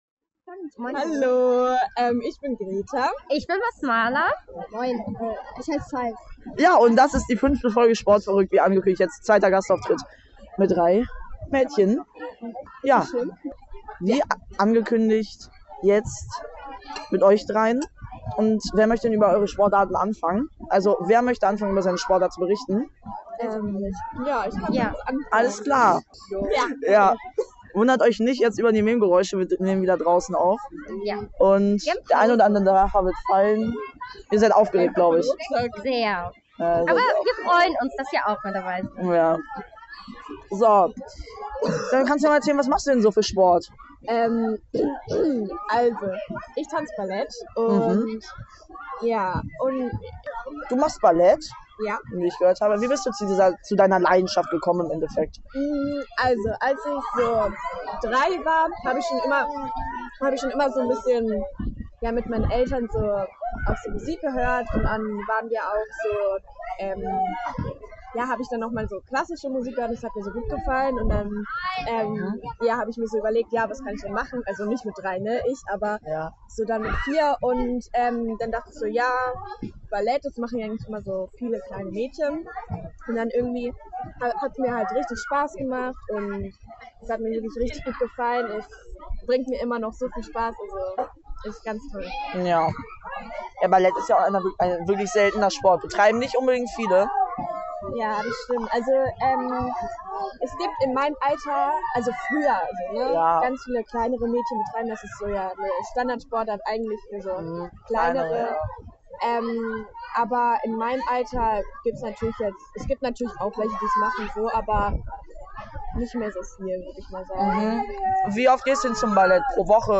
Hey ihr Sportverrückten, heute habe ich drei Mädels Interviewt.